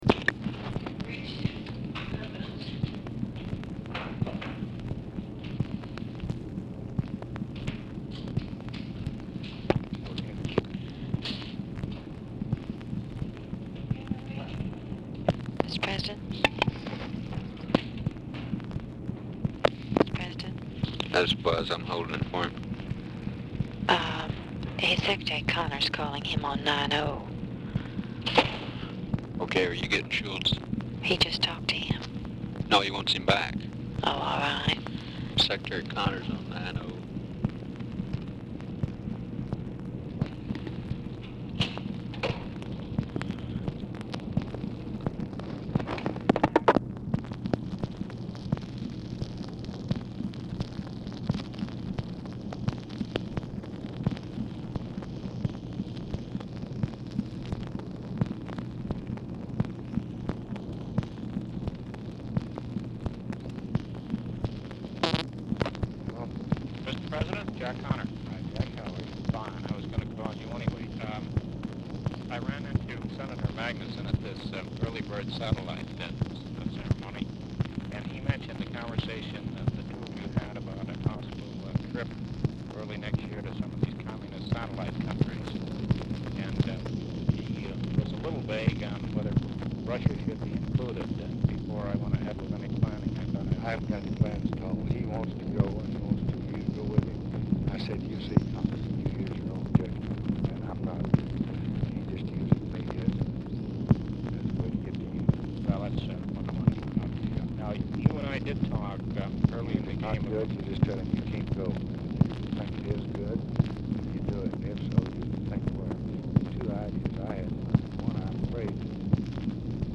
VERY POOR SOUND QUALITY
Format Dictation belt
Location Of Speaker 1 Oval Office or unknown location
Specific Item Type Telephone conversation